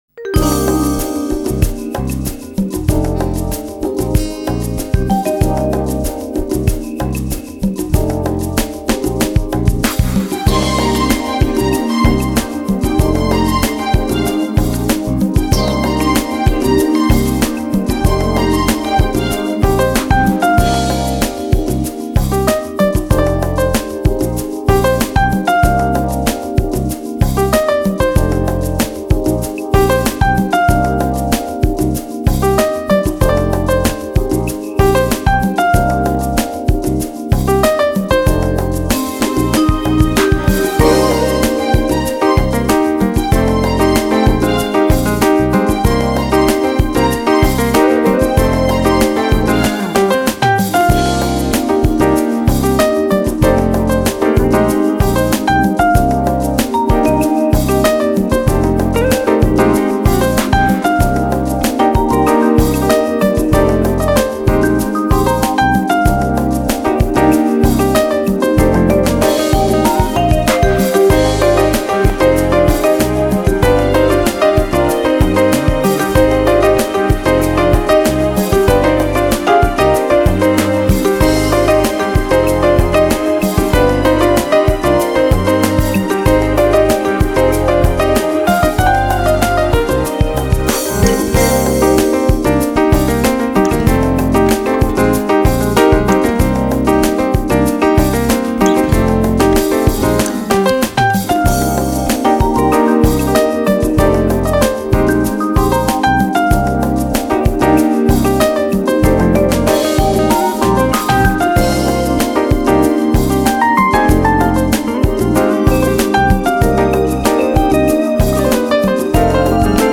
smooth jazz, Jazz+Funk